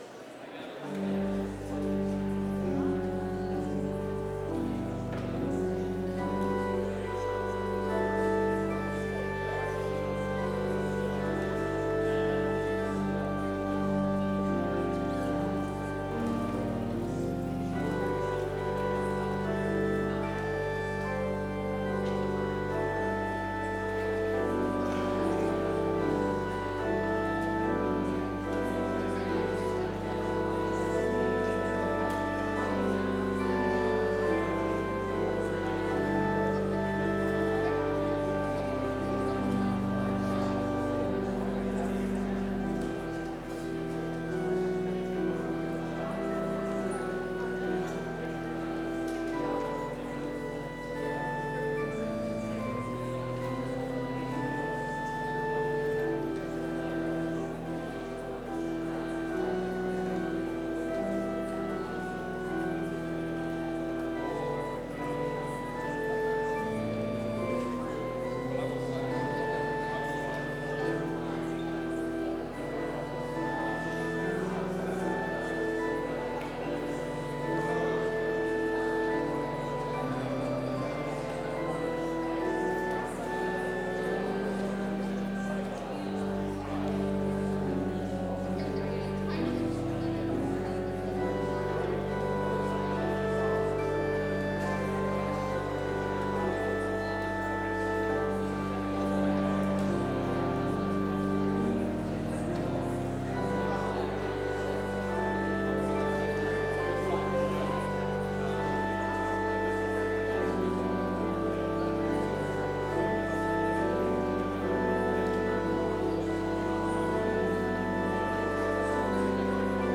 Complete service audio for Chapel - Tuesday, October 21, 2025